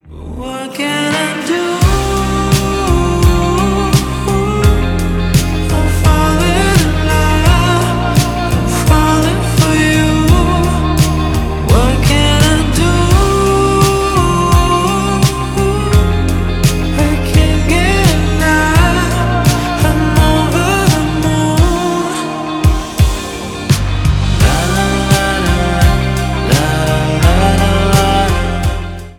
поп , инди